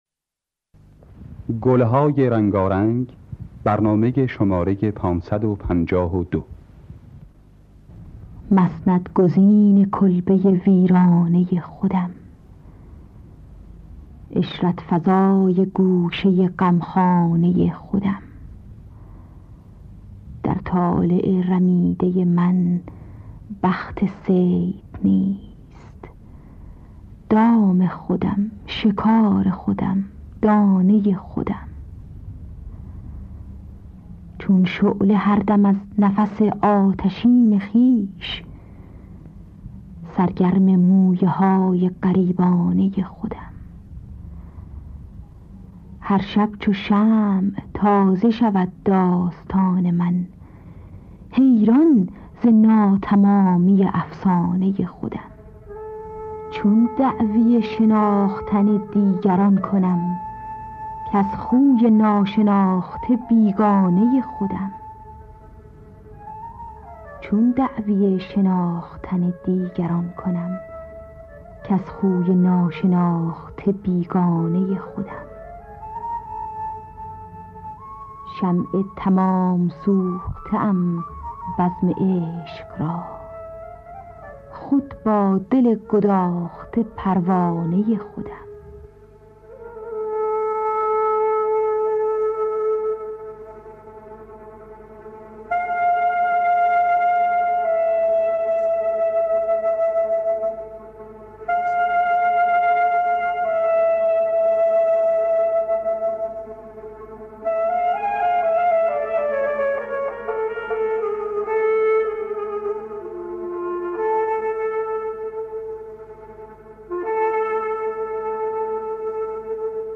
دانلود گلهای رنگارنگ ۵۵۲ با صدای حسین قوامی، سیما بینا در دستگاه چهارگاه.
خوانندگان: حسین قوامی سیما بینا